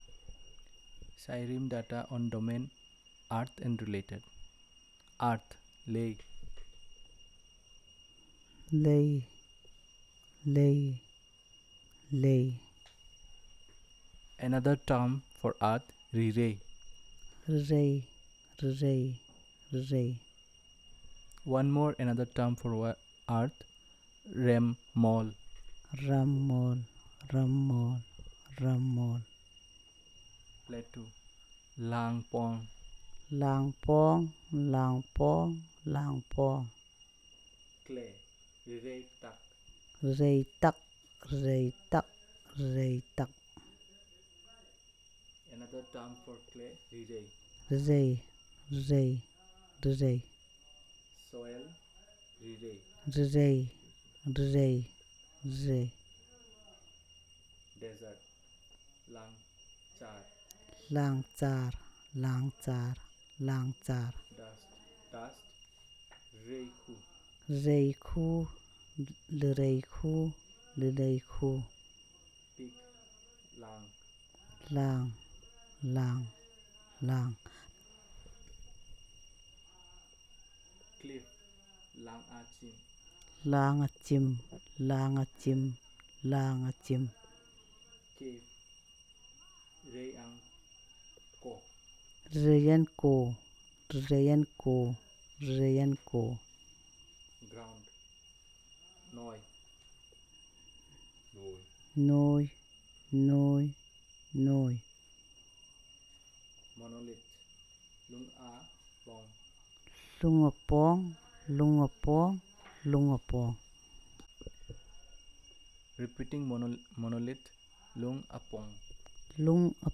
Elicitation of words about earth and related
dc.coverage.spatialTaranagpur
dc.description.elicitationmethodInterview method
dc.type.discoursetypeElicitation